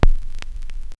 Sample Sound Effects
vinyl-needle-drop.mp3